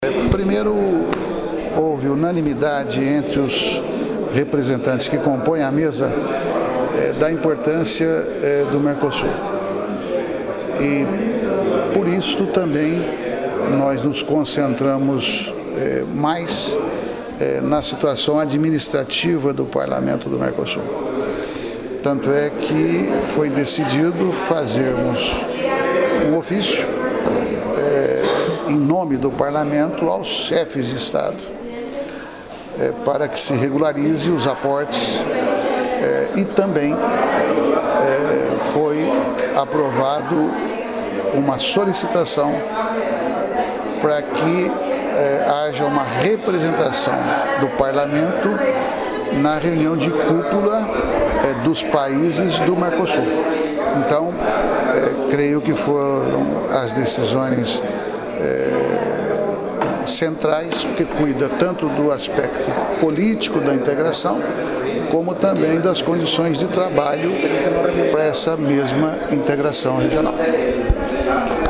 Parlamentario Arlindo Chinaglia, Vicepresidente por Brasil